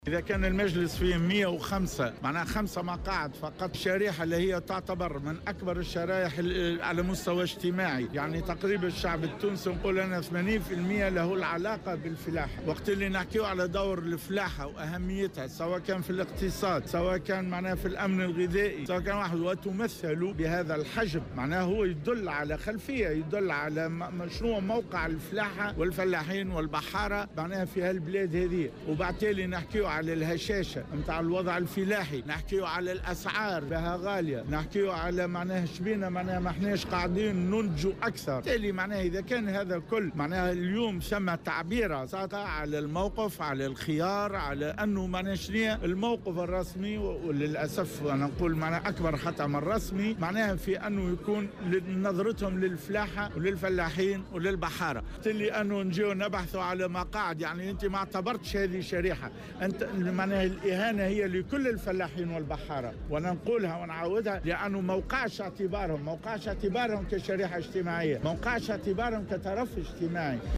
Dans une déclaration à Jawhara FM